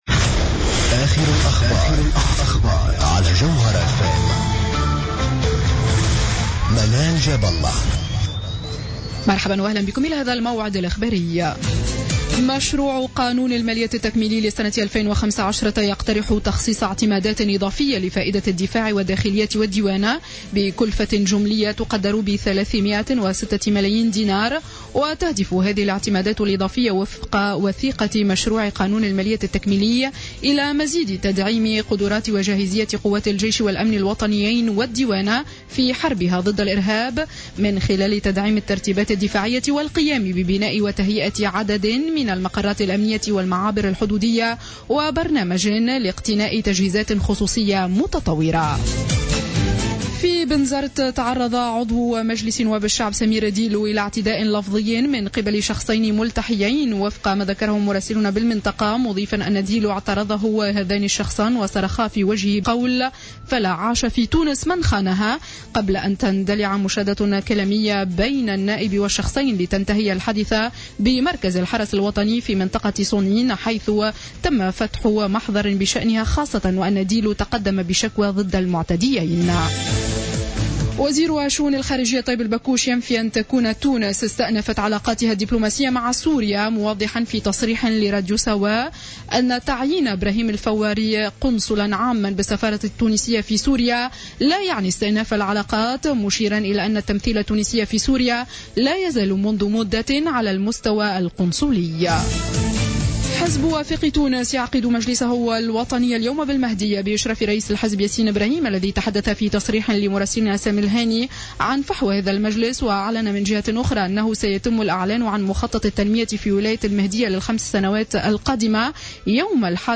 نشرة أخبار السابعة مساء ليوم الأحد 26 جويلية 2015